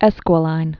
(ĕskwə-līn, -lĭn)